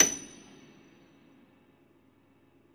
53c-pno28-F6.wav